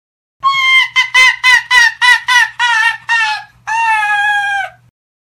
Risada de cachorro
Categoria: Risadas
Descrição: Ouça o som de um cachorro rindo, se você curtir pode fazer o download do mp3 de Risada de cachorro no link abaixo.
risada-de-cachorro-pt-www_tiengdong_com.mp3